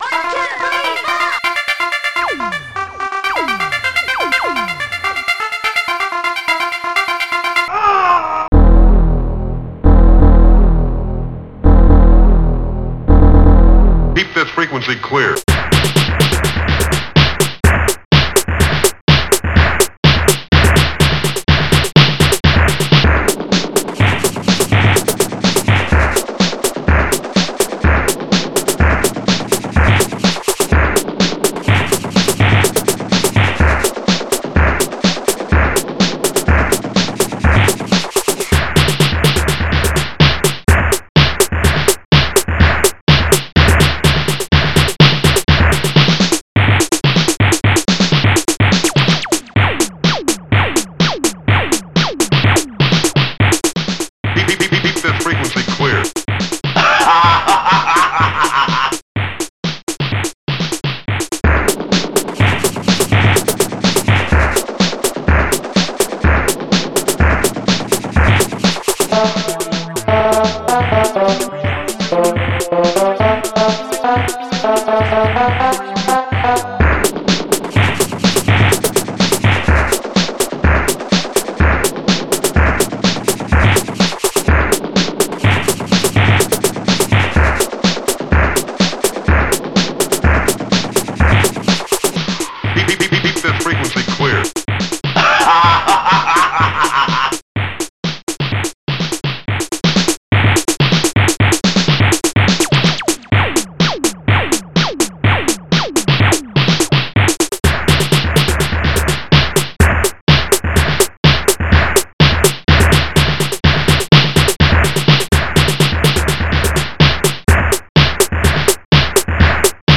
ST-08:SnareDrum
st-01:monsterbass
st-08:cowbell2